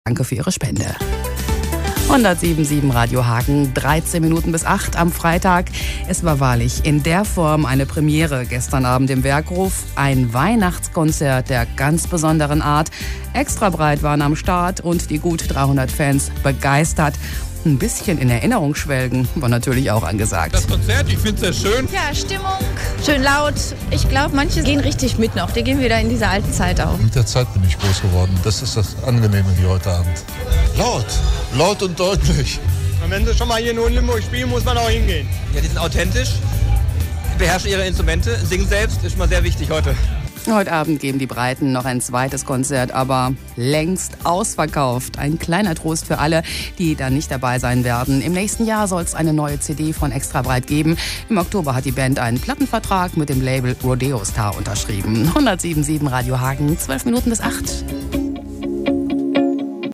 In den Lokalnachrichten auf Radio Hagen wurde am Freitag kurz über das Konzert berichtet.
Und auch einige Fans sind den Reportern vor das Mikro gelaufen und geben erste Eindrücke wieder.